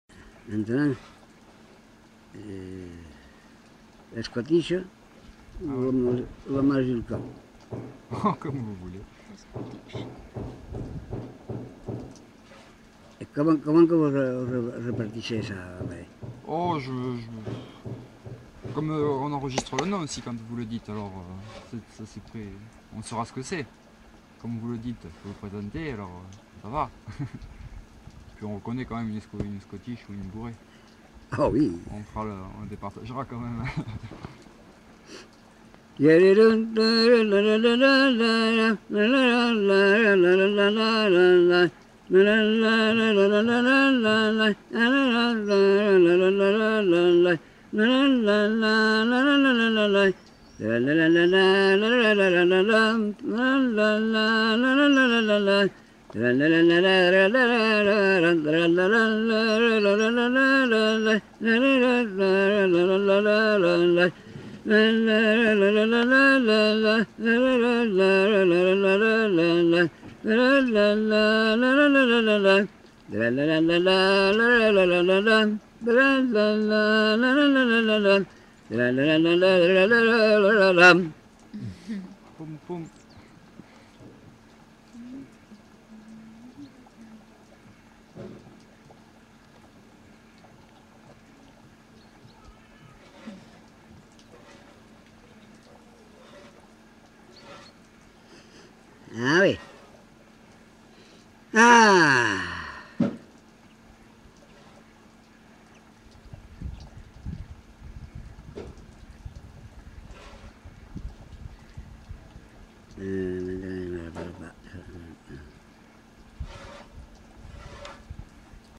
Genre : chant
Effectif : 1
Type de voix : voix d'homme
Production du son : fredonné
Danse : scottish
Ecouter-voir : archives sonores en ligne